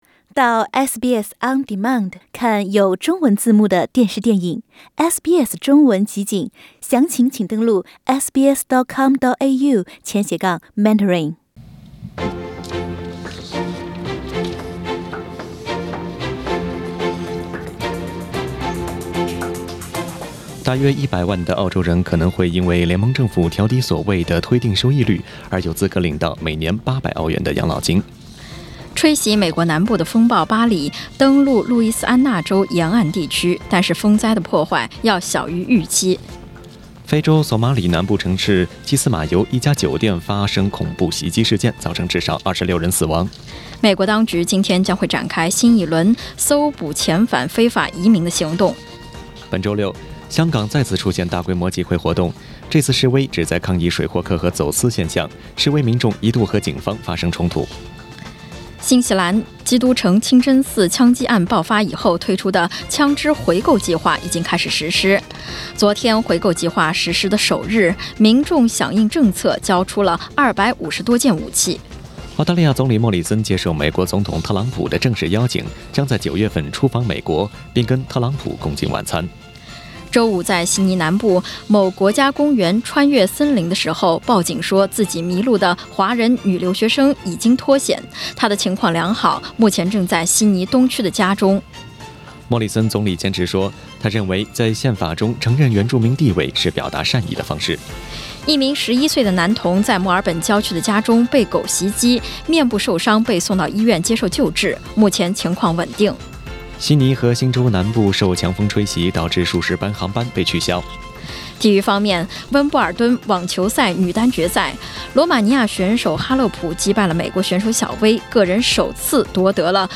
SBS 早新闻 （07月14日）